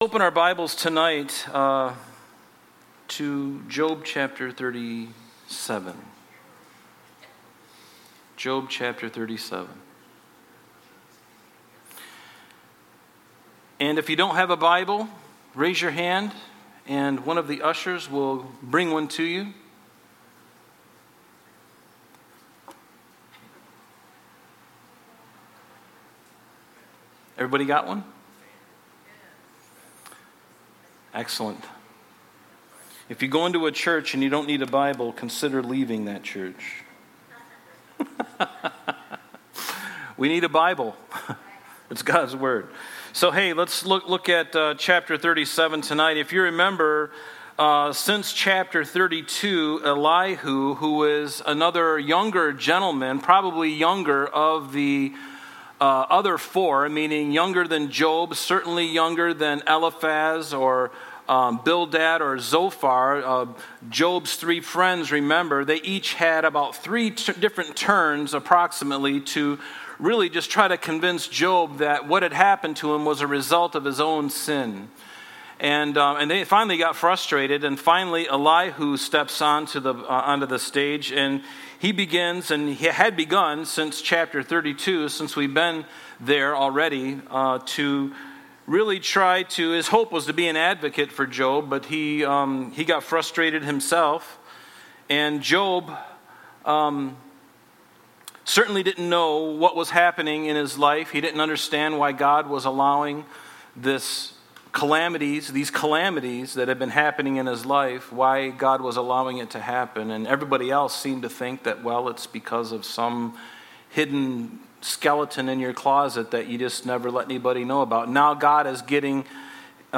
Thursday Night Bible Study